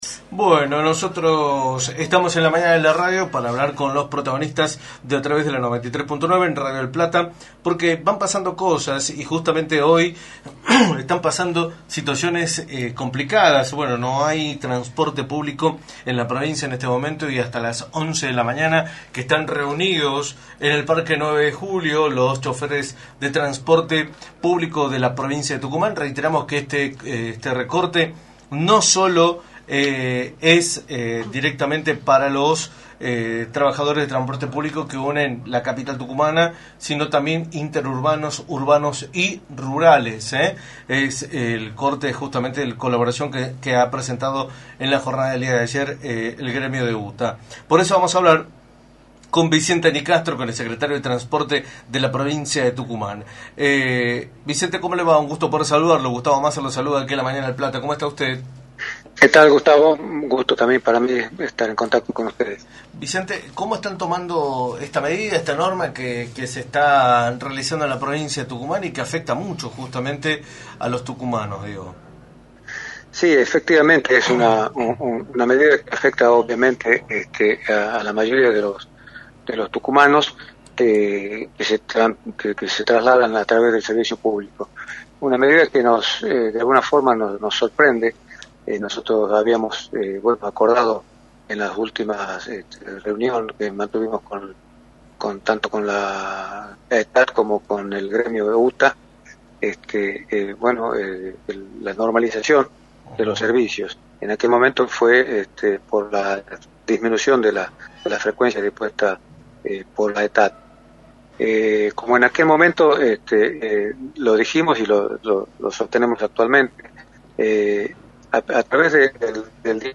Vicente Nicastro, Secretario de Transporte, analizó en Radio del Plata Tucumán, por la 93.9, las repercusiones de la medida de fuerza que está llevando a cabo UTA, la cual implica una restricción en el servicio.